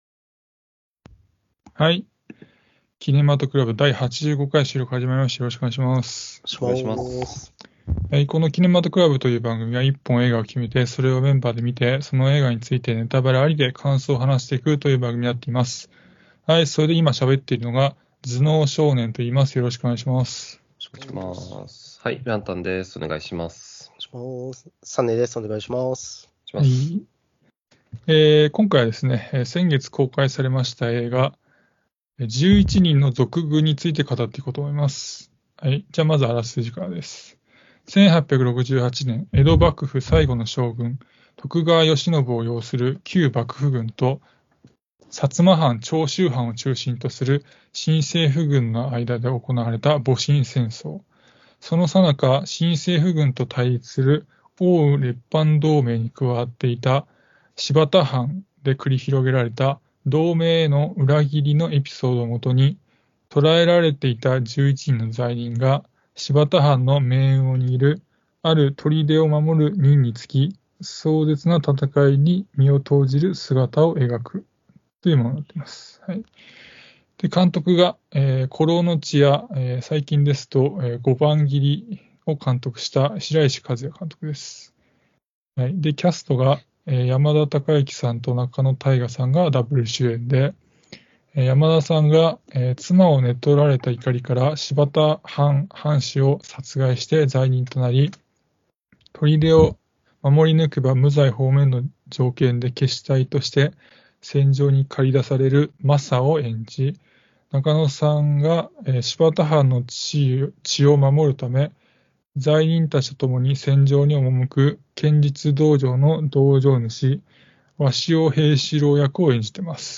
映画好きの男達が毎回映画の課題作を決め、それを鑑賞後感想を話し合います。